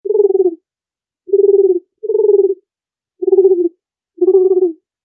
So hört sich eine Turteltaube an: